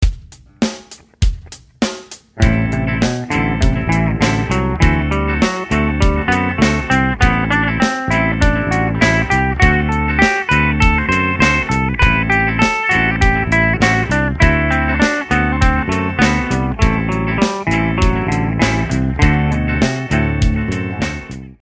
The first exercise plays through the mode pattern skipping one note at a time which also happens to play scale steps in 3rds.
dorian_a_3rds.mp3